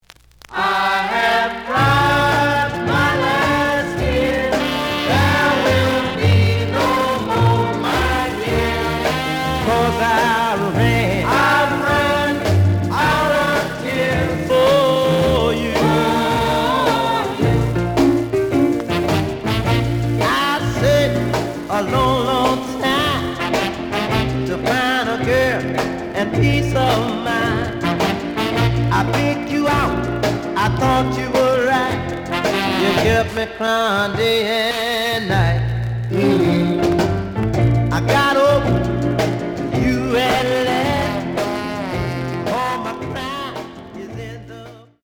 The audio sample is recorded from the actual item.
●Genre: Rhythm And Blues / Rock 'n' Roll
Some noticeable noise on both sides.